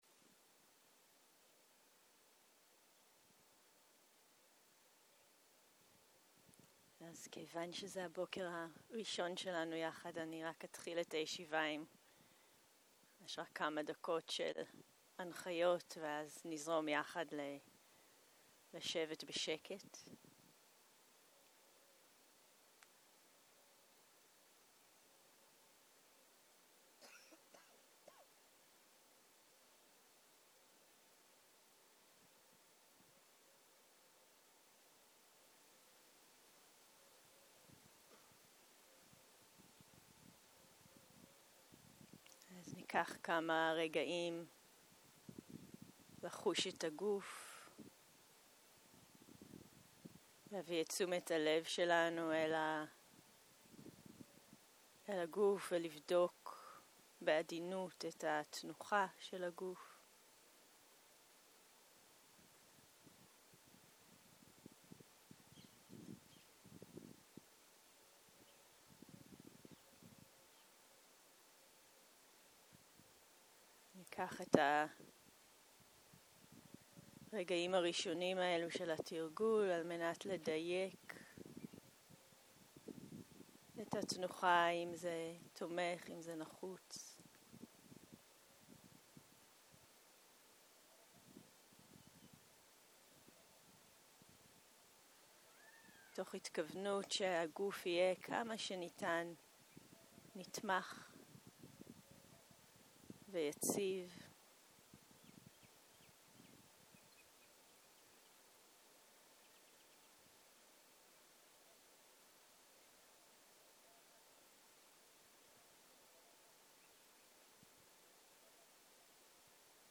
בוקר - מדיטציה מונחית - איסוף אל הגוף